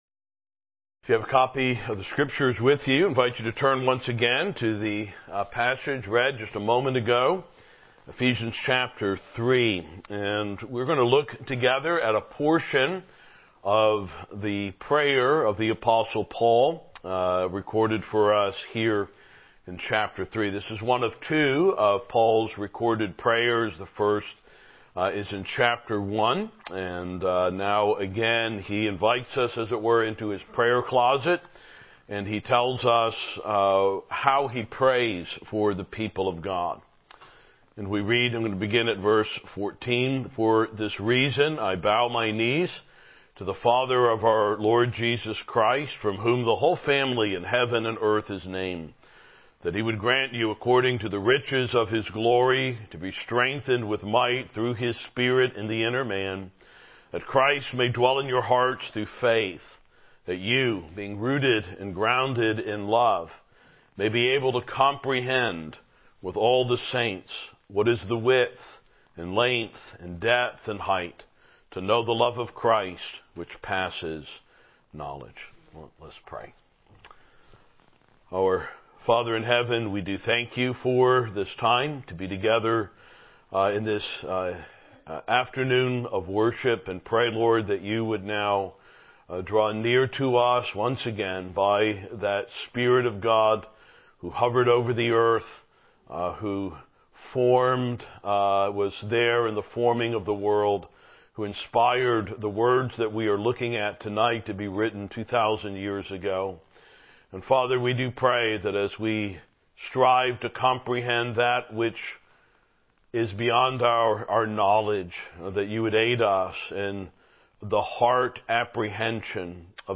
Ephesians 3:14-19 Service Type: Evening Worship « My Grace Is Sufficient Chapter 2.1